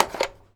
phone_pickup_handle_01.wav